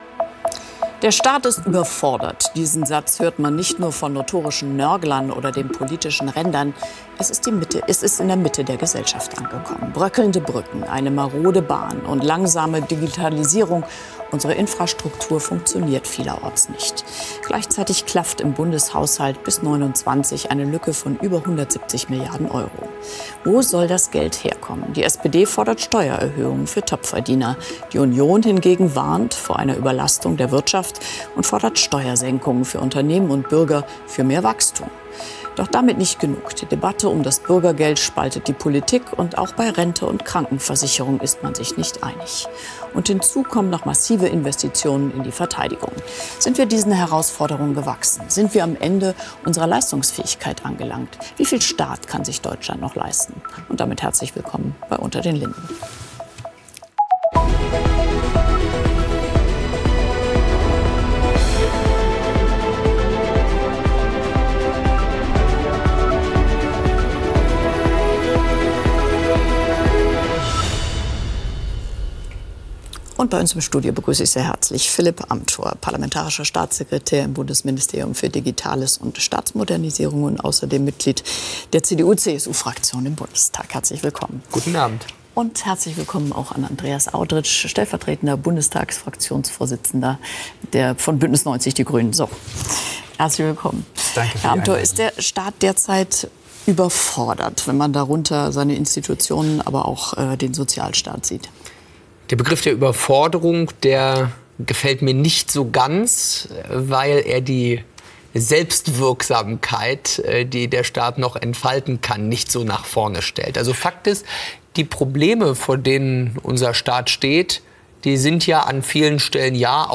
Der Parlamentarische Staatssekretär beim Bundesminister für Digitales und Staatsmodernisierung, Philipp Amthor (CDU), und der stellvertretende Co-Fraktionsvorsitzende der Bündnisgrünen im Bundestag, Andreas Audretsch (Grüne), diskutieren